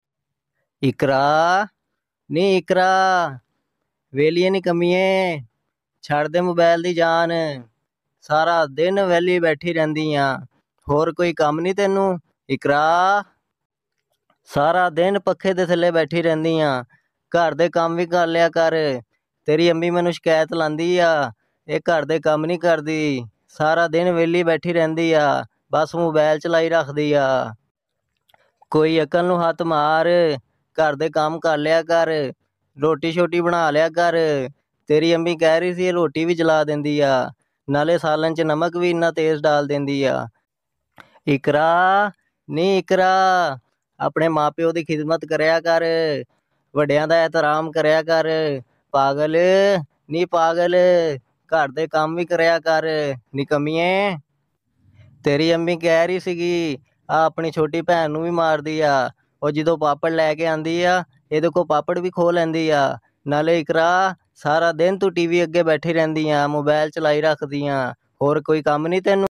Goat Calling Name Iqra Funny sound effects free download